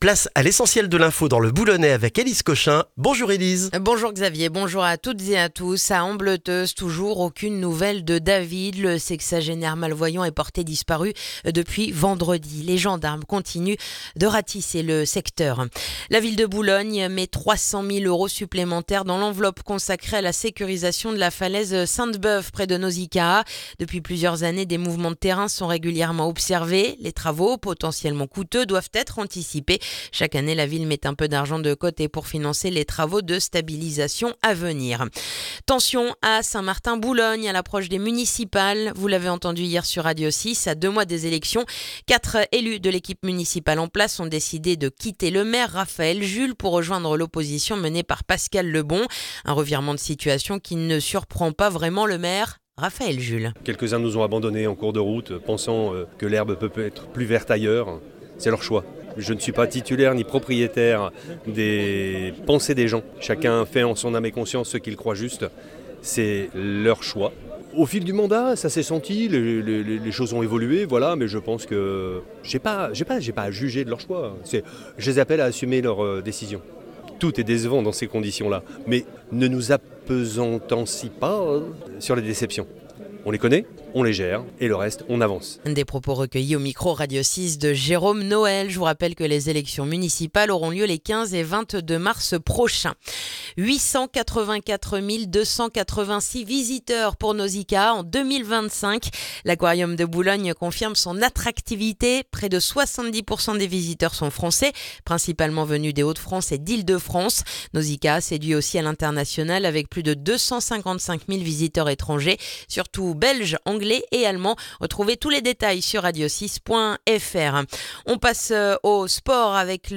Le journal du mardi 20 janvier dans le boulonnais